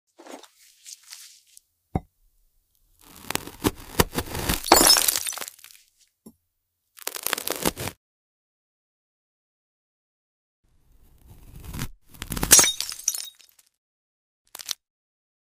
Cutting a glass burger and sound effects free download
Cutting a glass burger and the sound is unreal.